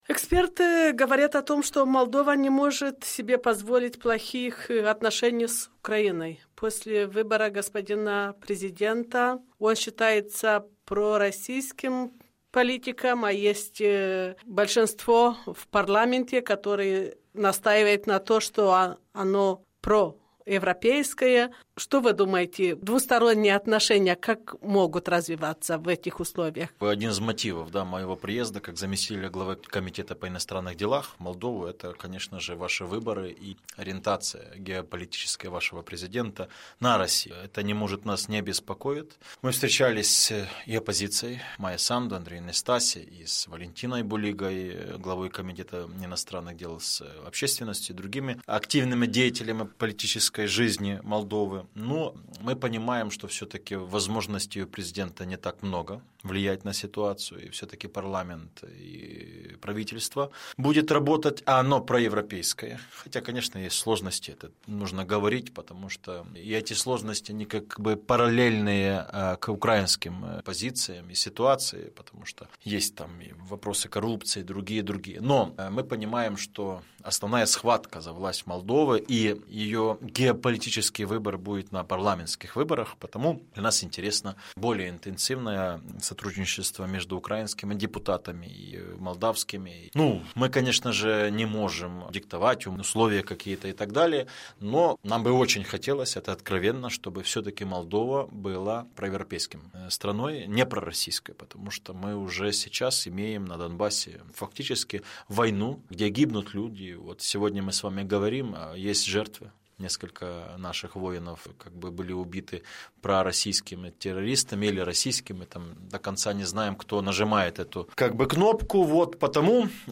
Interviu cu Igor Guz